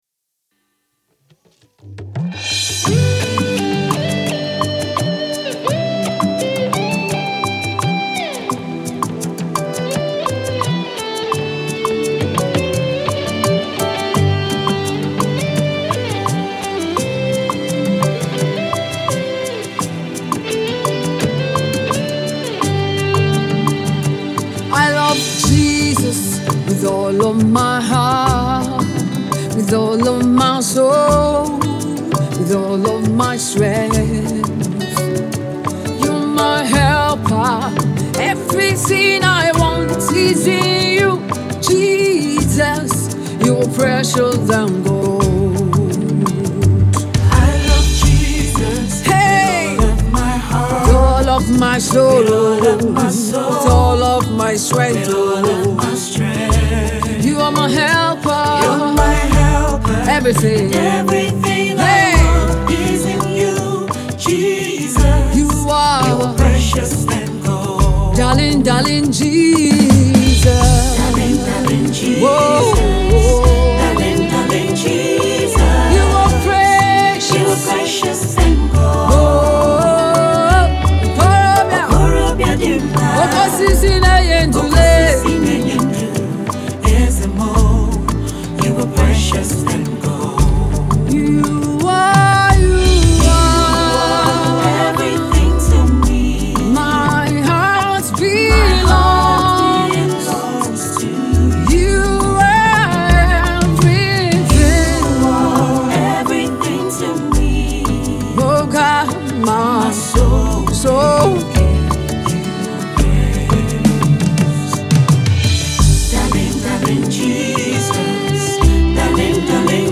Rising gospel sensation
The Afrocentric mid-tempo